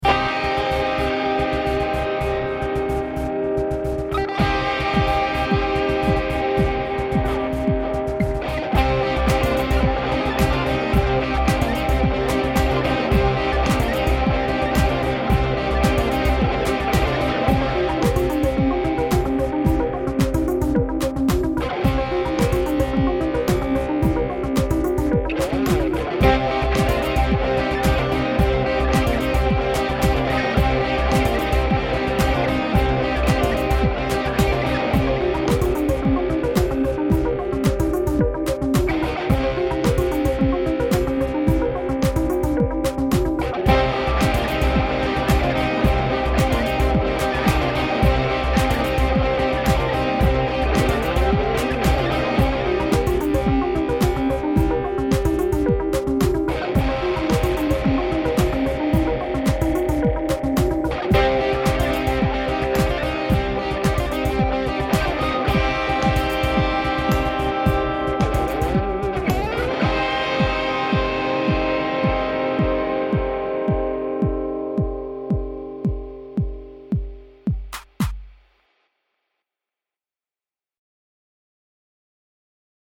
The dynamic level is reduced, and so are the peaks and valleys in the waveform. It is a much more uniform dynamic level (less dynamic range).
The dynamic level is not quite as loud as the original, but the waveform is now much more dense both horizontally and vertically.
Each audio track is now Compressed and Normalized (mp3 format).